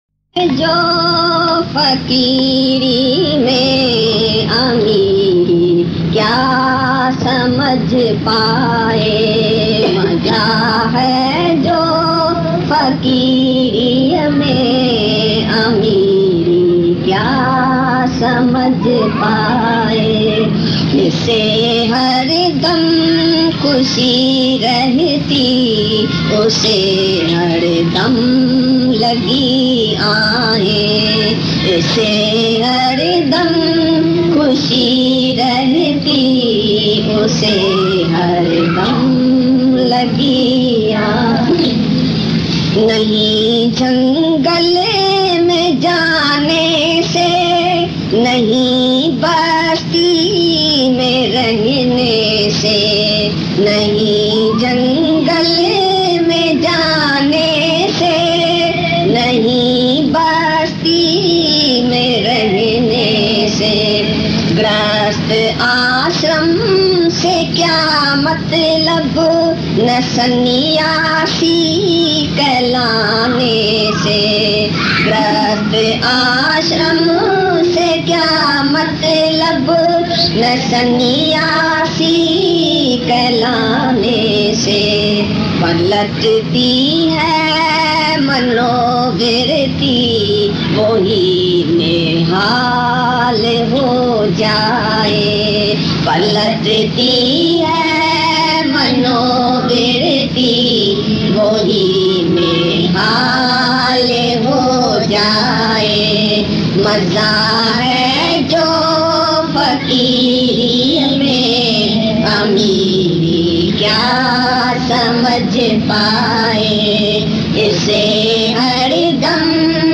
Geeta Shyam Bhajans Lyrics